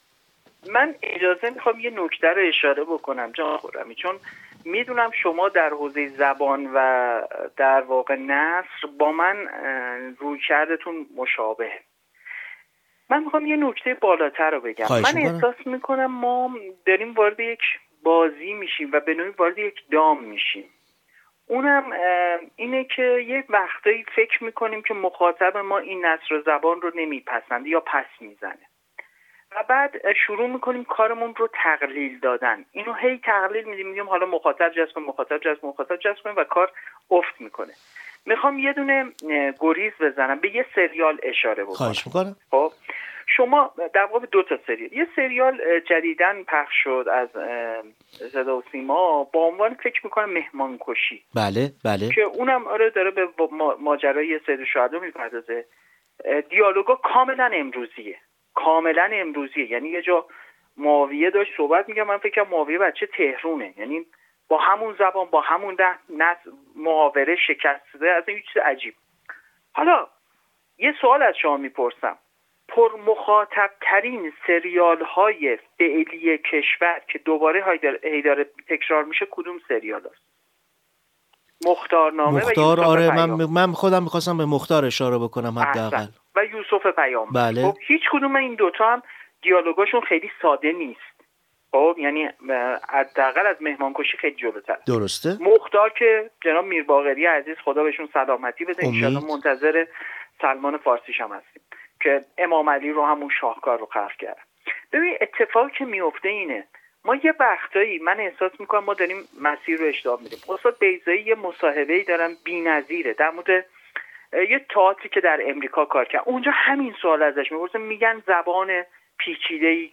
در ادامه؛ این گفت‌وگوی صریح و بی پرده بر بلندای این دو نور تا ابد جاوید از خاطرتان می‌گذرد.